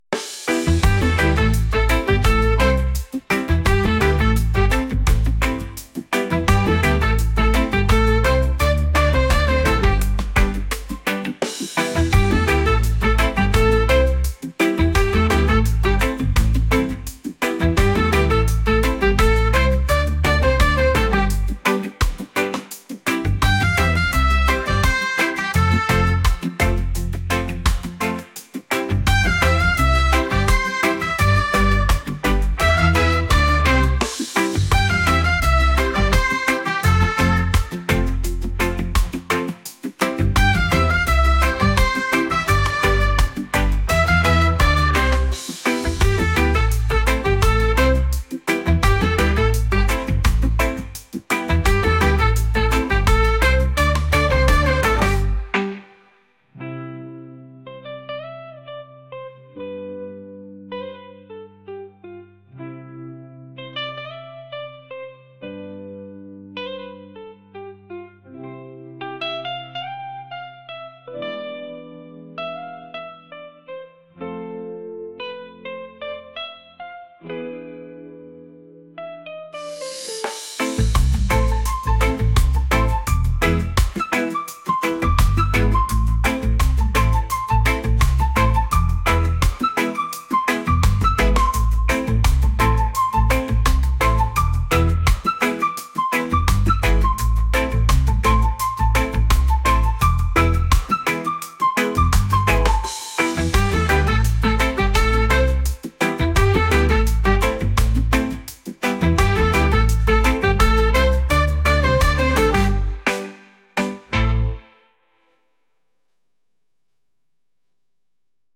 reggae | pop | electronic